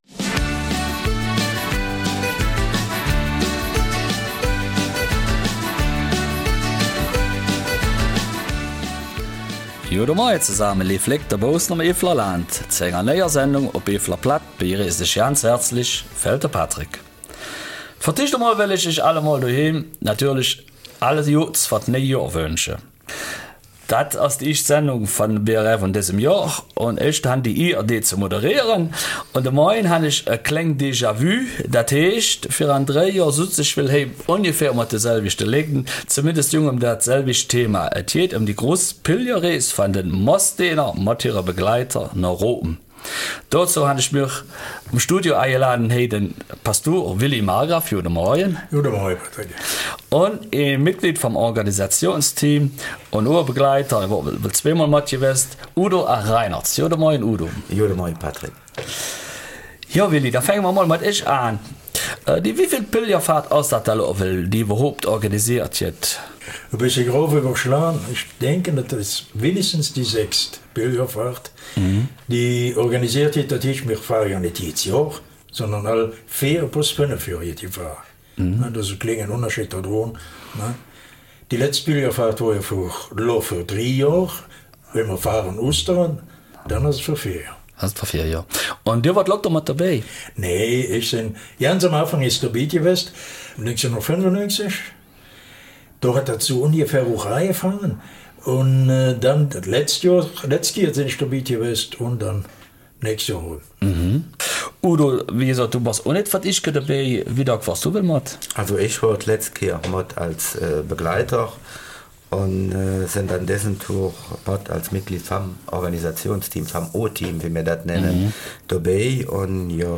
Eifeler Mundart: Pilgerreise der Messdiener nach Rom